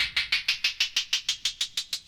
Pitch Tick.wav